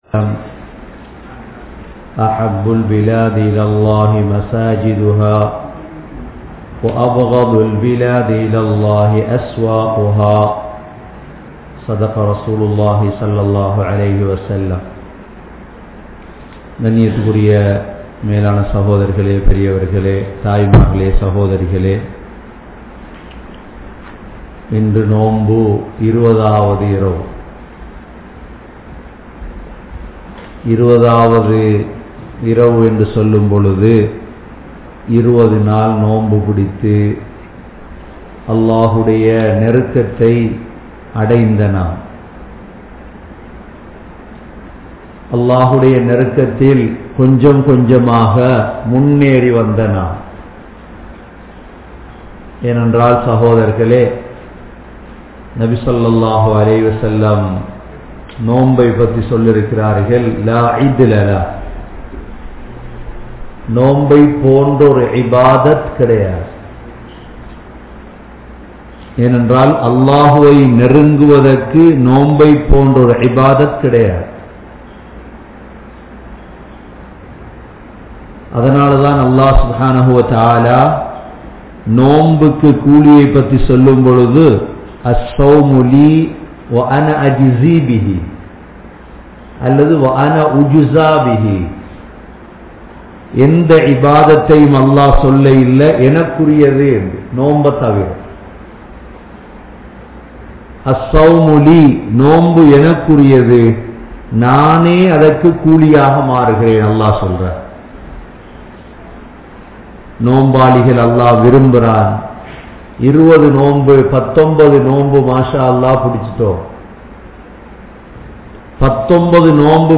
Ihthikaaf(Part 01) (இஃதிகாப்) | Audio Bayans | All Ceylon Muslim Youth Community | Addalaichenai
Canada, Toronto, Thaqwa Masjidh